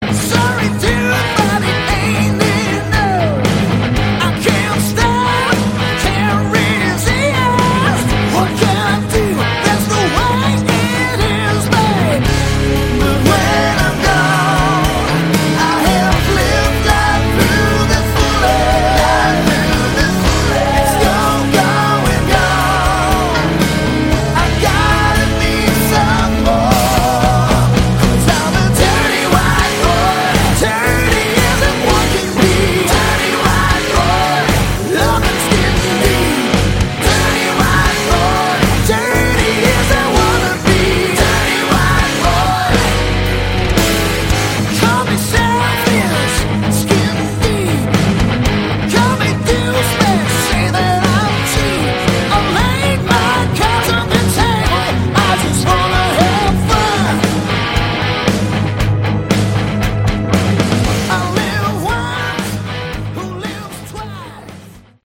Category: Melodic Hard Rock
guitar
vocals
drums
bass
keyboards